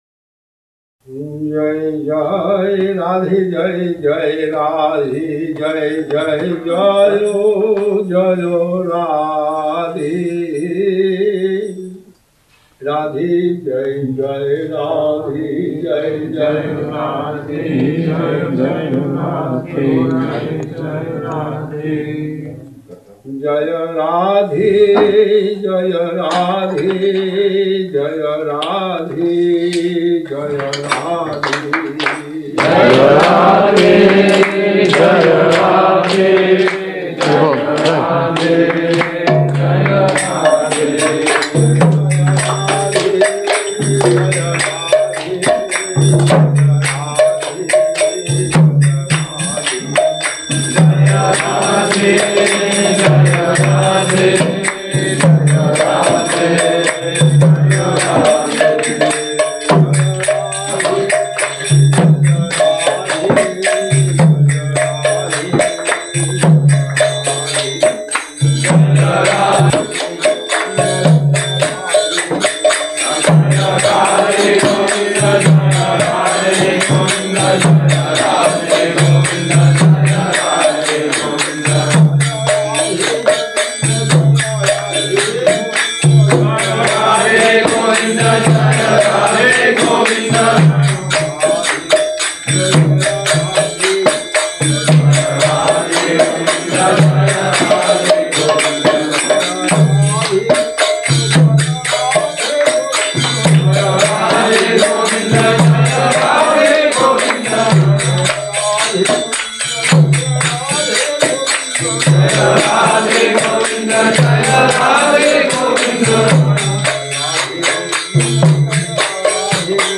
Москва, август 1996 года. Киртан в день явления Шримати Радхарани.